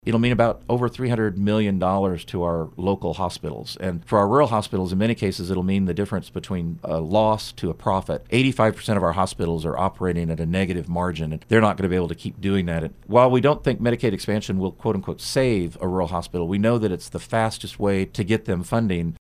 Kansas Lt. Gov. Lynn Rogers stopped by KMAN this morning to discuss various items proposed in Gov. Laura Kelly’s State of the State address and 2020 budget proposal.